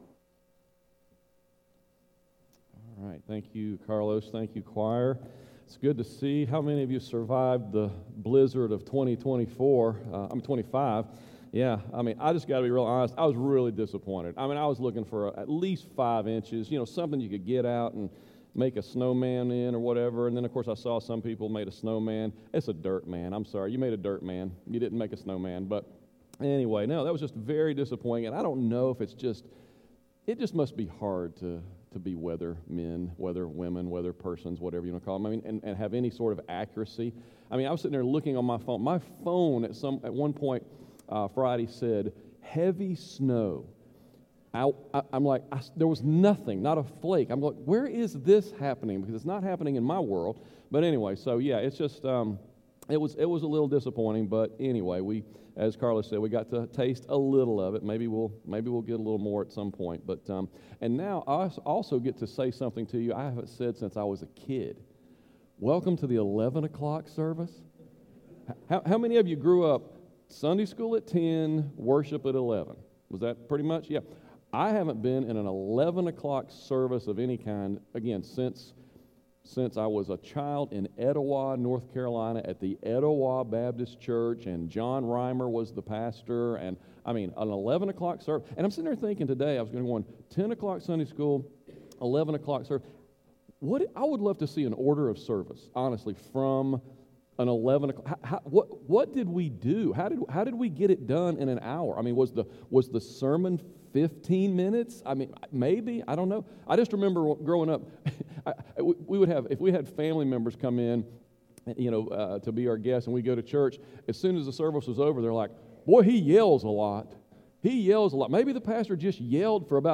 Sermons | Hampton Heights Baptist Church